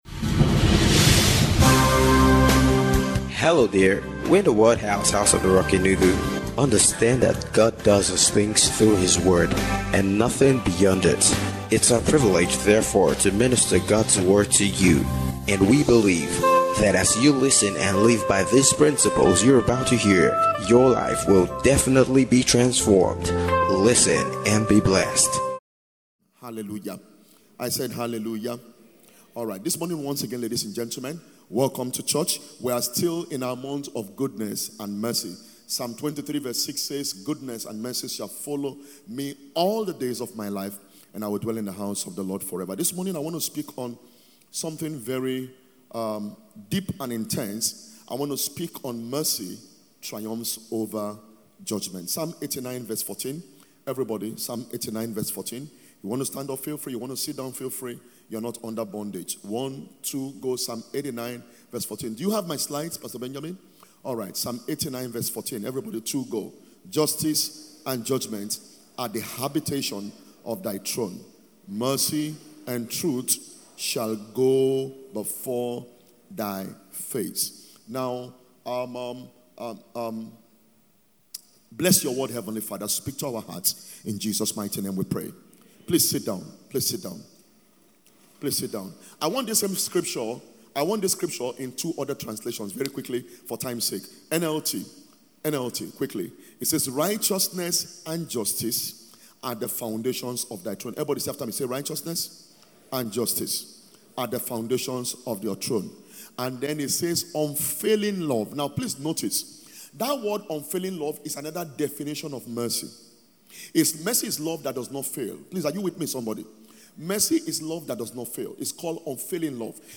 GOODNESS AND MERCY - 1ST SERVICE